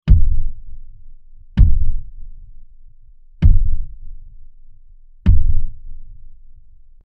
Giant Stomping Footsteps 02
Giant_stomping_footsteps_02.mp3